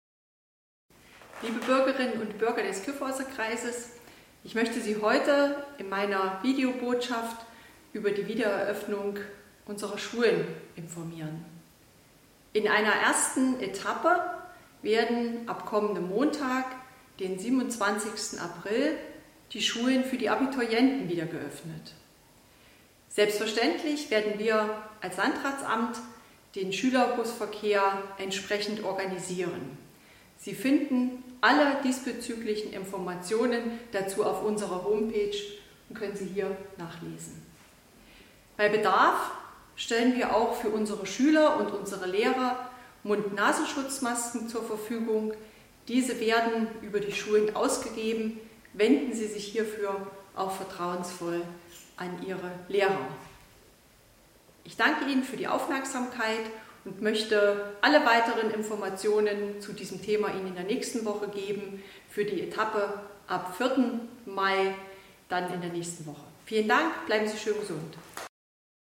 Landrätin Antje Hochwind-Schneider (SPD) kündigt in ihrer 12. Videobotschaft den Schulbeginn für die Abiturklassen an und hier gibt es weitere Tipps für den Verkauf der Schutzmasken für die Gebiete An der Schmücke und Verwaltungsgemeinschaft Greußen (ohne Stadt Greußen)...